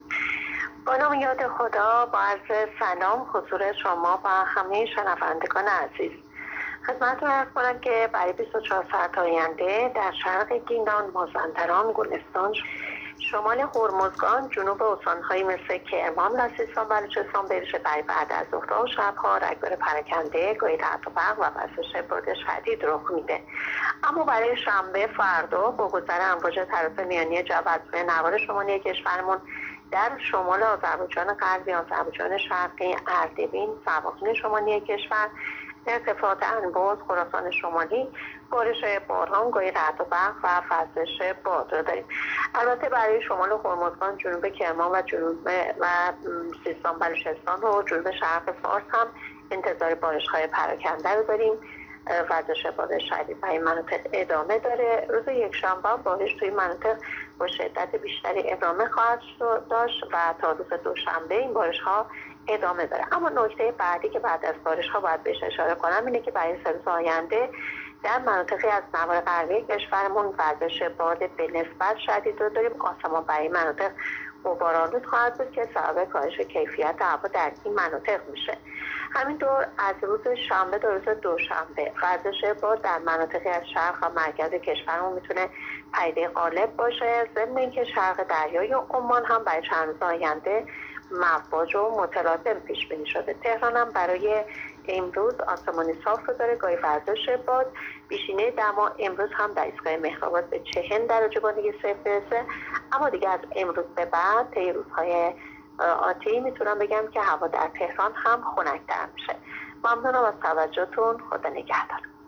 گزارش رادیو اینترنتی پایگاه خبری از آخرین وضعیت آب‌وهوای سی‌ و یکم تیرماه؛